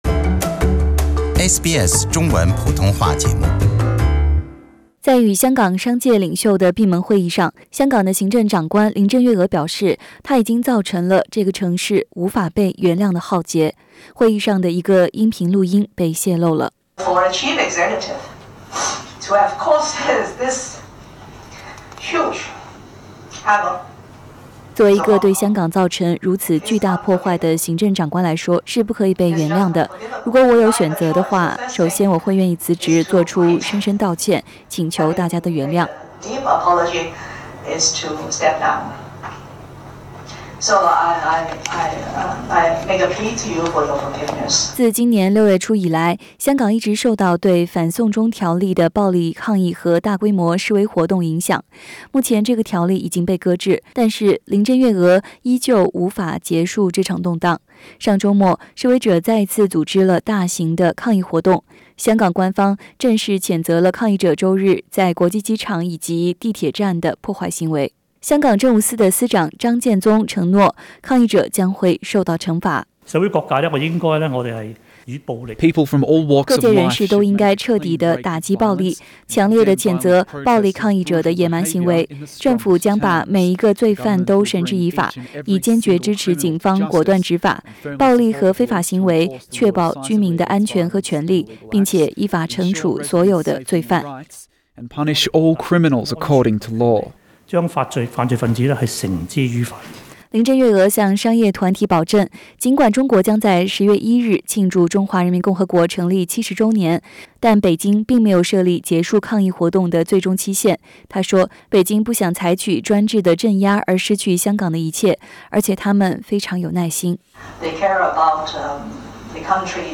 在一段被泄露的与香港商界领袖的闭门会议录音中，陷入困境的香港行政长官林郑月娥承认，抗议活动持续之际，如果有机会，她会辞去工作。